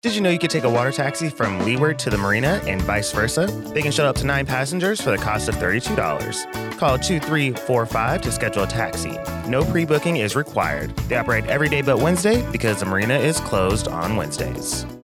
A radio spot